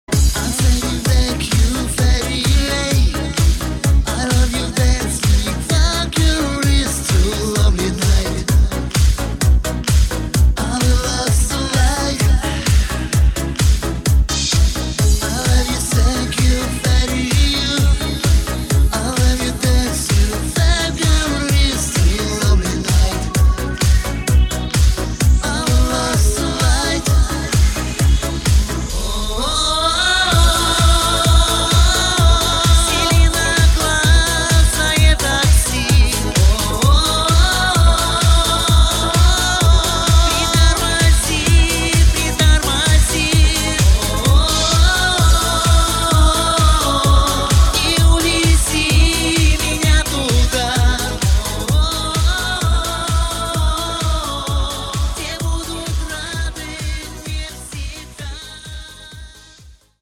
• Качество: 320, Stereo
зажигательные
диско
mash up
Eurodance